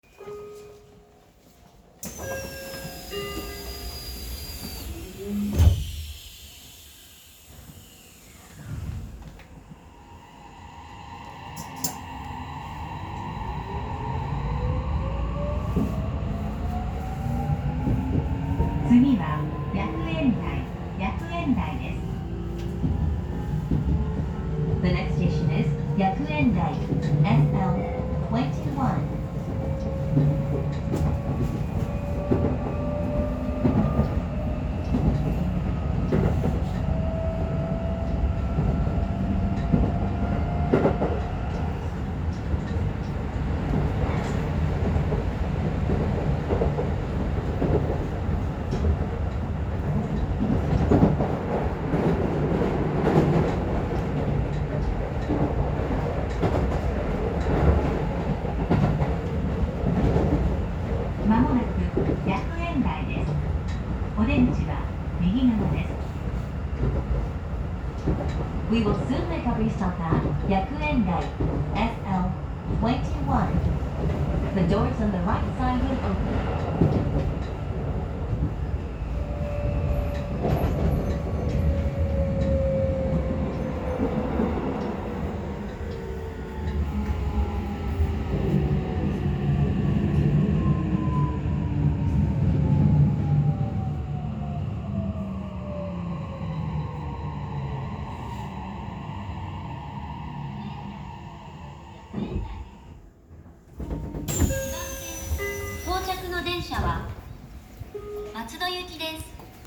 〜車両の音〜
・80000形走行音
【新京成線】前原→薬園台（1分49秒：3.34MB）
見た目こそ京成3100形と全く同じですが、モーターは異なっており、三菱SiCを採用しています。
モーター音自体はE235系や都営5500形のそれと比べればまだ大人しい方かと。